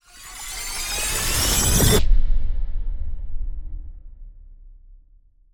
Charging.wav